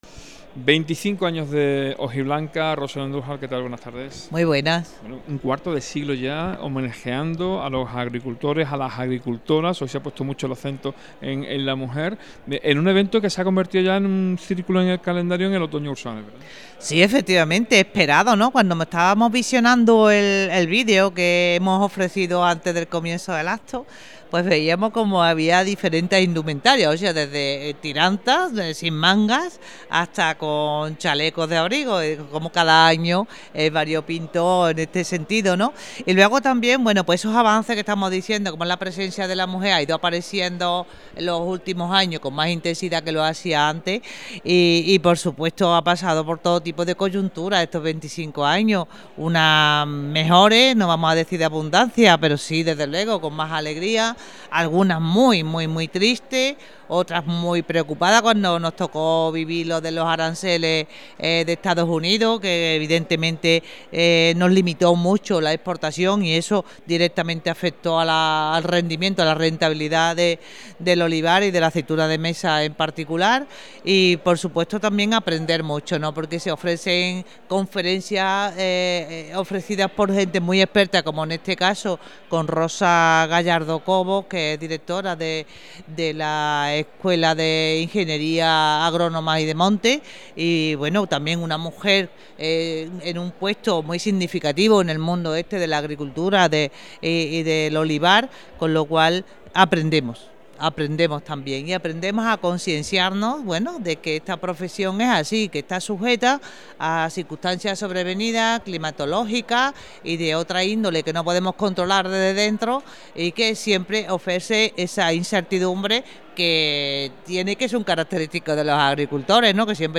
El Salón de Actos de la Casa de la Cultura de Osuna acogió ayer tarde la entrega de premios del XXV (25) Concurso Hojiblanca de Oro, organizado por el Ayuntamiento de Osuna en colaboración con la SAT Santa Teresa.
Rosario Andújar es la alcaldesa de Osuna